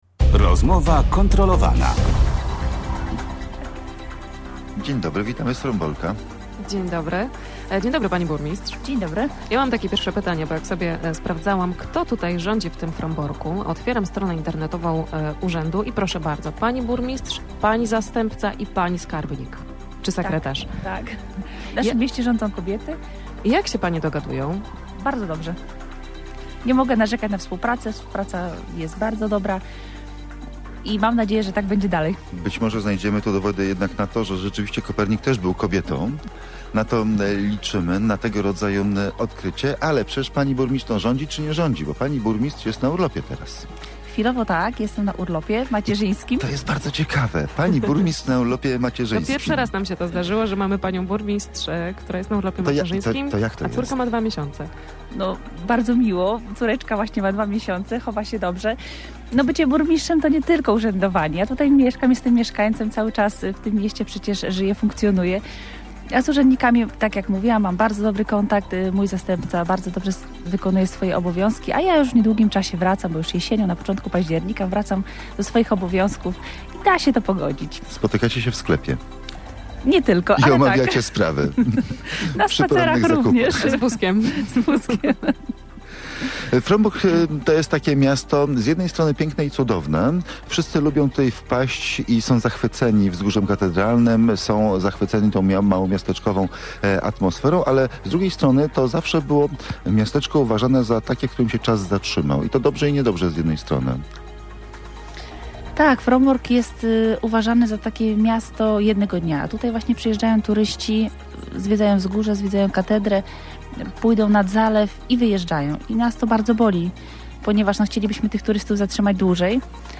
– Frombork przez wielu turystów jest uważany za miasto „jednego dnia”. Ludzie przyjeżdżają, zwiedzają Wzgórze Katedralne, zobaczą Zalew i wyjeżdżają – mówiła w Radiu Gdańsk Małgorzata Wrońska, burmistrz Fromborka.